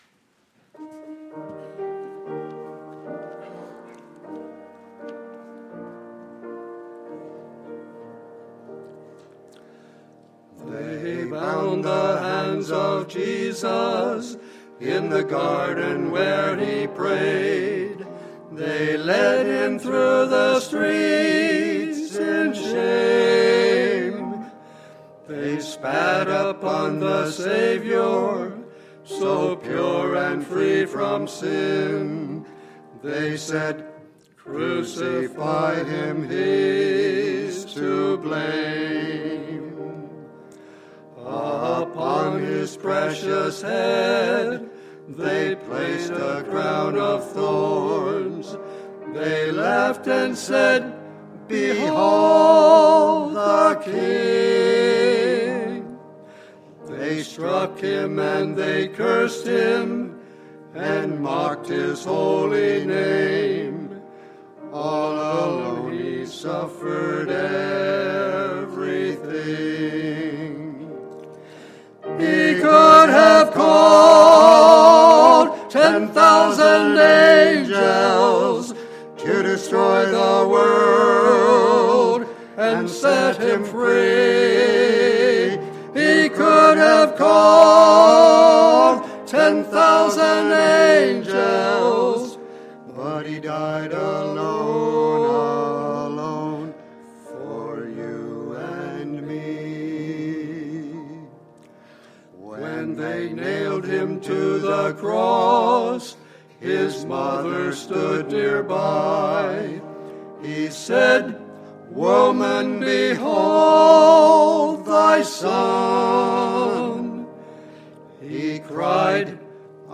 The Old Rugged Cross – Crucifixion Service
Sermons